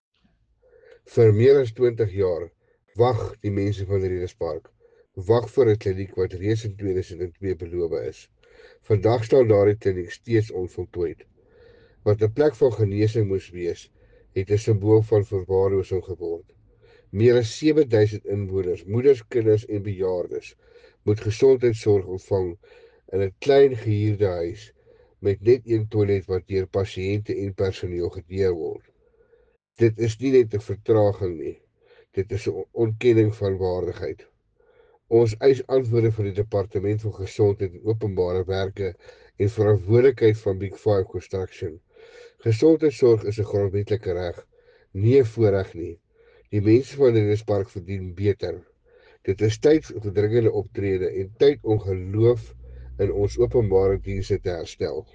Afrikaans soundbites by Cllr Werner Janse van Rensburg and